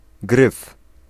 Ääntäminen
Ääntäminen Tuntematon aksentti: IPA: [ɡrɨf] Haettu sana löytyi näillä lähdekielillä: puola Käännös Konteksti Ääninäyte Substantiivit 1. neck musiikki US 2. griffin 3. bar British US 4. calk Suku: m .